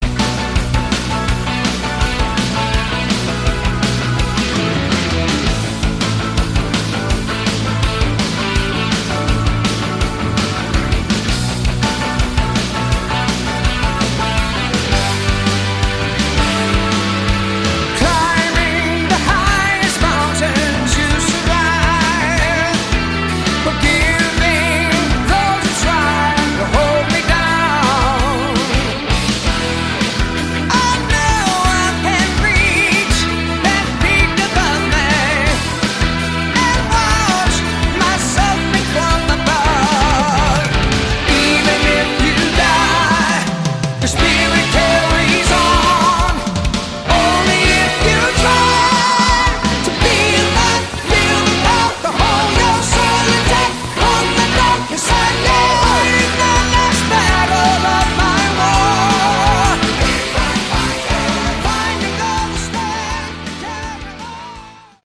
Category: AOR
lead vocals
keyboards, background vocals
bass, fretless bass
drums
lead and rhythm guitars